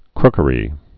(krkə-rē)